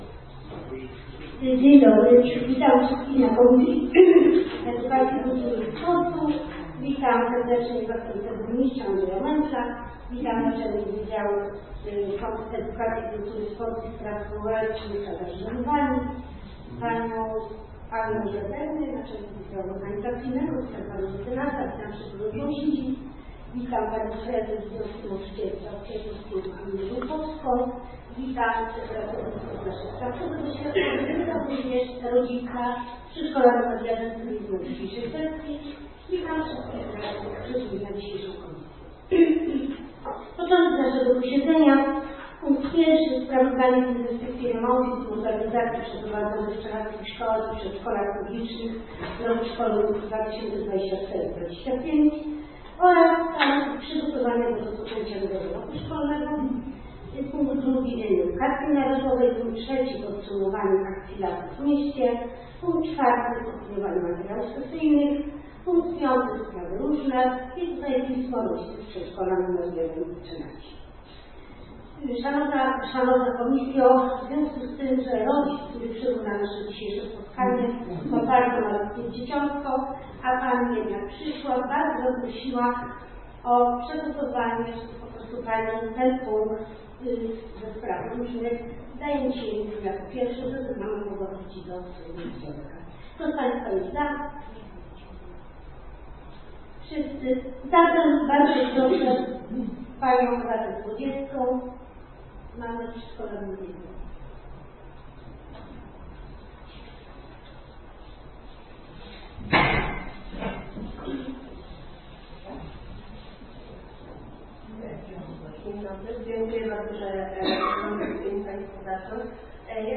Posiedzenie Komisji Edukacji, Kultury i Sportu w dniu 22 września 2025 r.